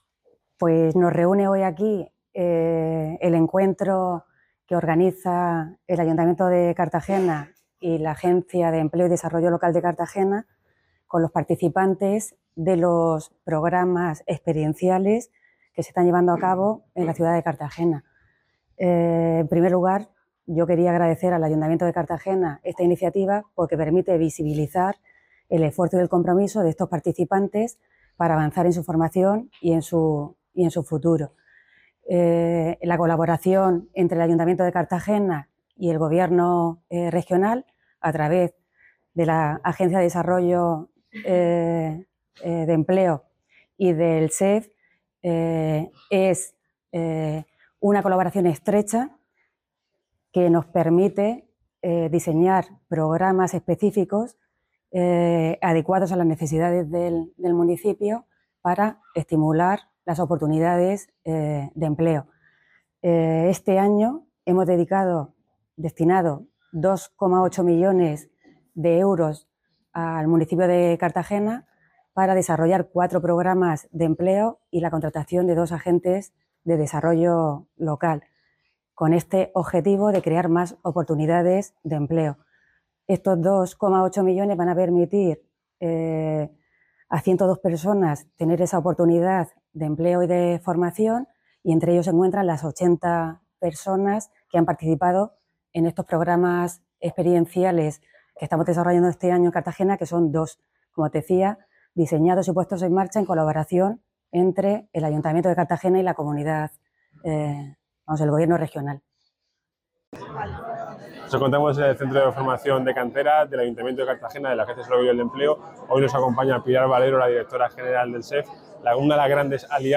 Enlace a Declaraciones de Pilar Valero y Álvaro Valdés Jornada ADLE
El concejal de Empleo, Formación y Contratación, Álvaro Valdés,realizó estas declaraciones este miércoles 10 de diciembre durante una jornada, junto a la directora general del SEF, Pilar Valero, en el Centro de Formación Ocupacional de Canteras, donde se mostró el trabajo de los 80 alumnos que participan actualmente en los programas mixtos de empleo y formación.